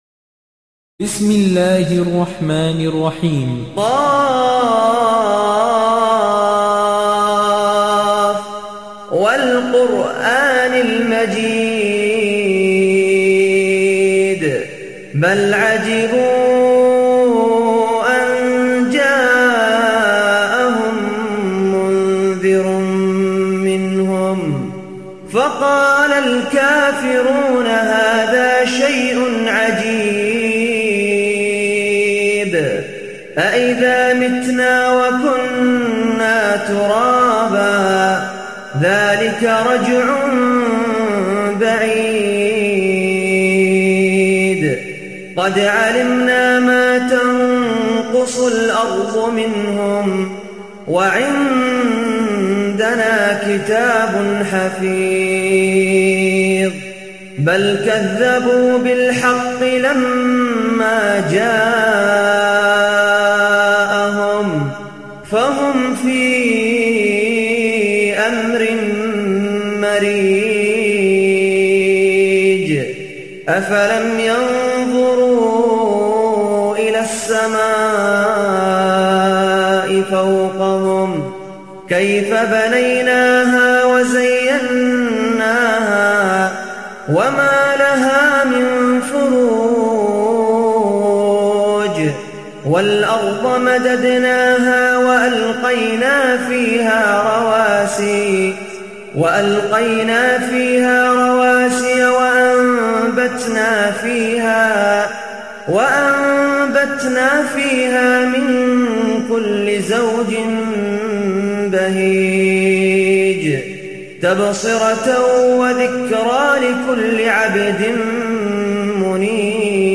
Beautiful Recitation with English Translation.